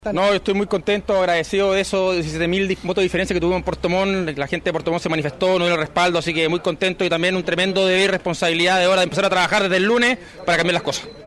El electo jefe comunal de Puerto Montt, Rodrigo Wainghrait, fue uno de los primeros en manifestar su opinión sobre este triunfo que tuvo su sector en el balotaje.